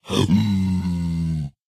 Minecraft Version Minecraft Version latest Latest Release | Latest Snapshot latest / assets / minecraft / sounds / mob / zombified_piglin / zpigangry4.ogg Compare With Compare With Latest Release | Latest Snapshot
zpigangry4.ogg